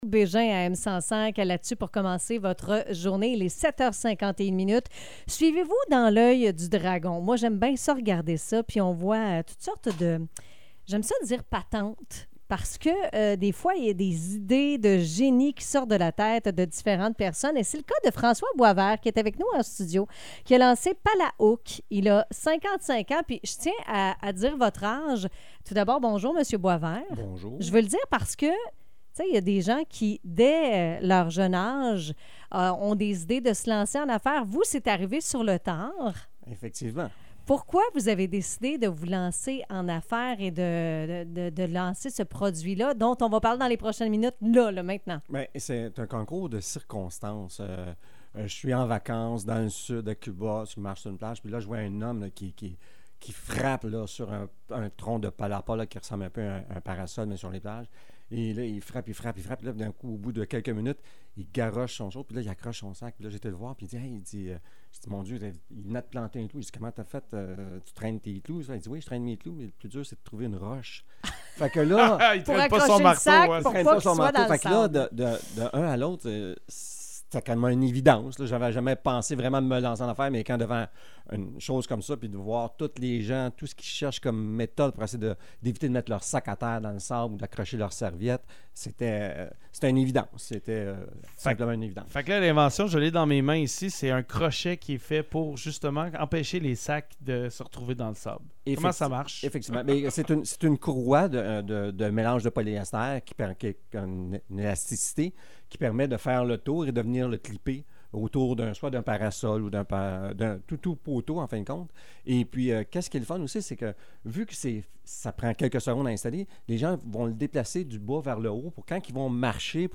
Entrevue-Palahook-2025_.mp3